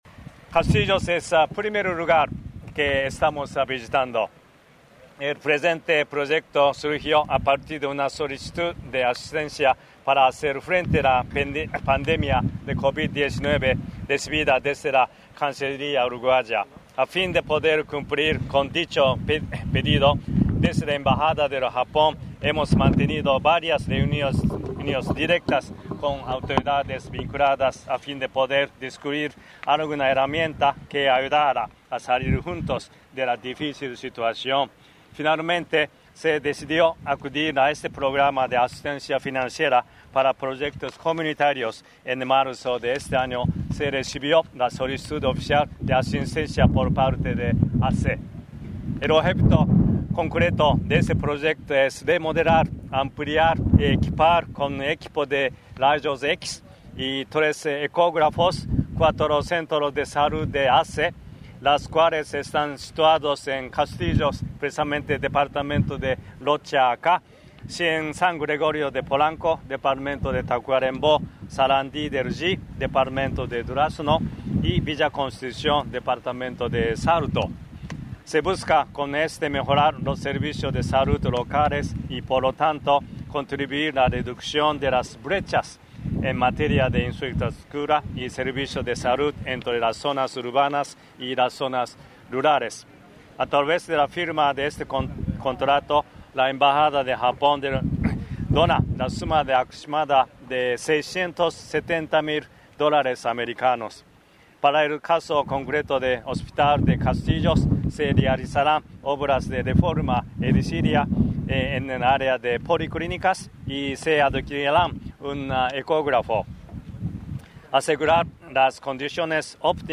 Declaraciones del presidente de ASSE, Leonardo Cipriani, en Rocha